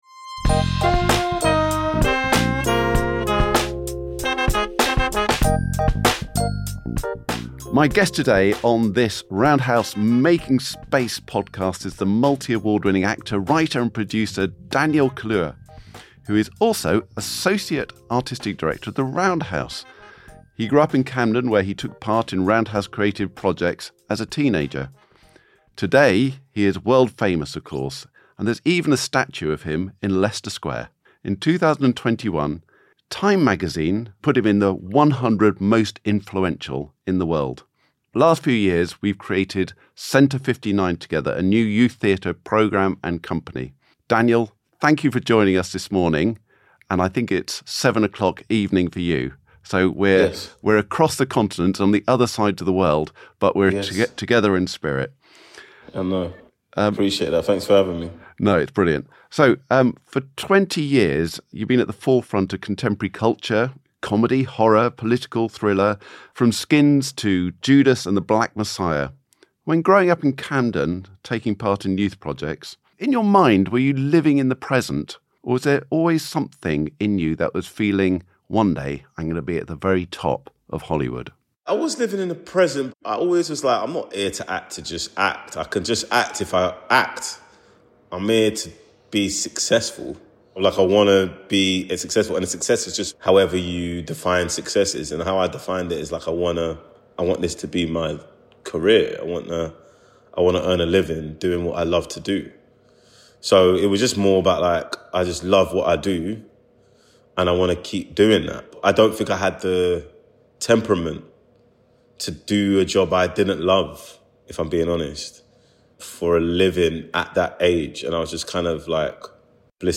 Making Space is a six-episode series, featuring intimate conversations with leading artists and thinkers connected to the Roundhouse.
Guest: Daniel Kaluuya